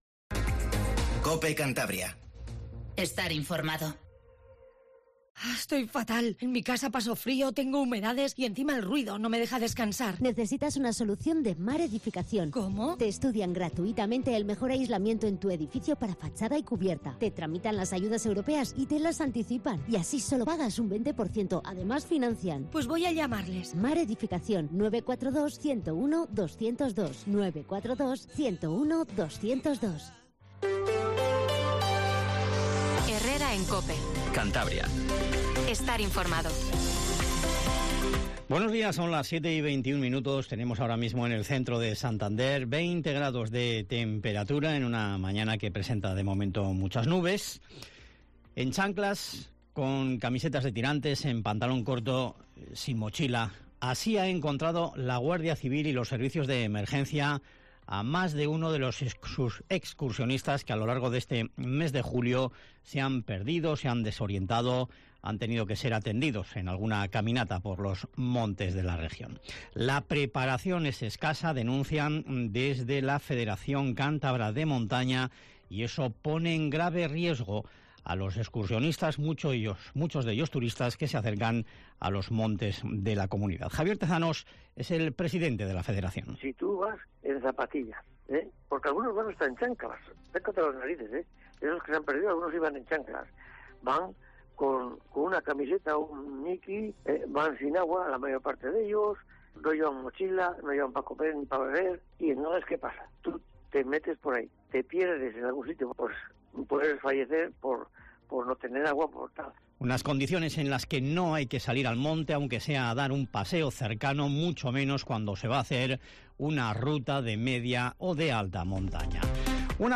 Informativo Matinal Cope 07:20